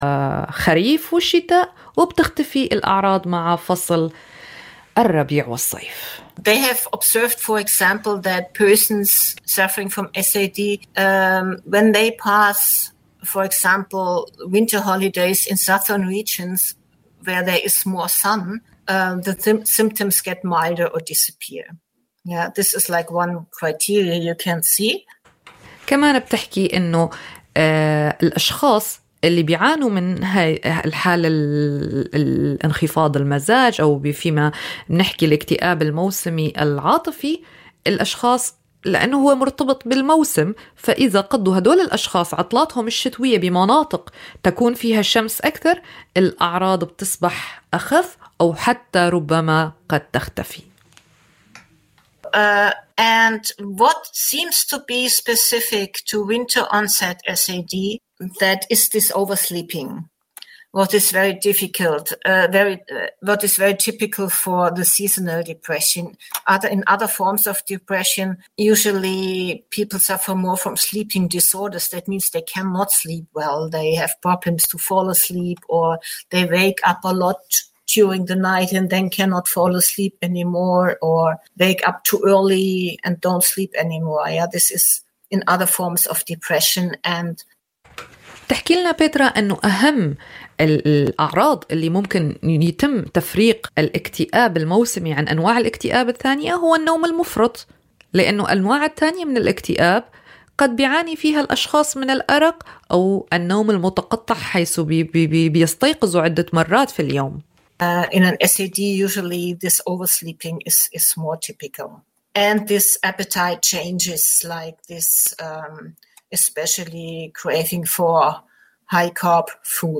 Seit 2016 gibt es bei unserem Schwesternradio Radio Corax die mehrsprachige Sendung Common Voices. Es ist eine Sendung von Gefl�chteten und MigrantInnen in Halle und Umgebung.